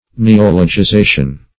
Neologization \Ne*ol`o*gi*za"tion\, n. The act or process of neologizing.
neologization.mp3